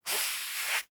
slide (3).wav